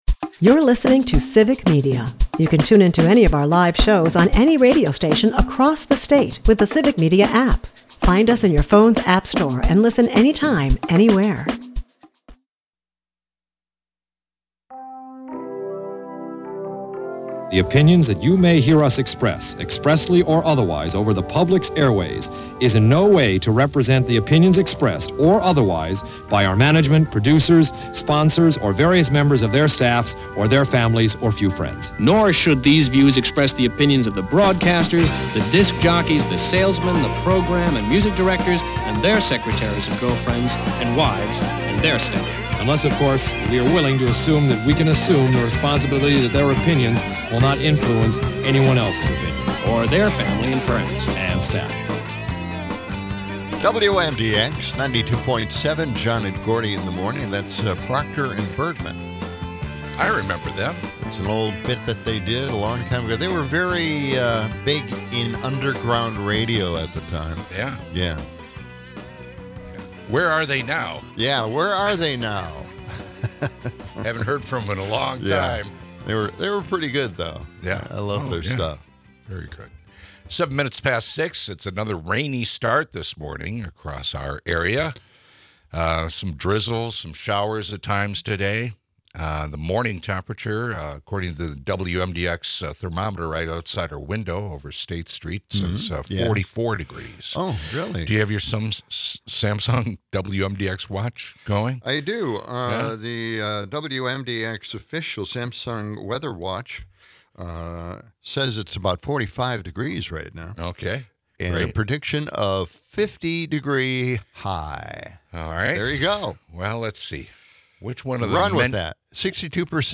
The episode kicks off with an unfortunate weather report and a nod to classic comedy duo Proctor and Bergman.
Amidst banter, they fondly remember George Wendt, a Cheers icon, celebrating his legacy with clips from the show.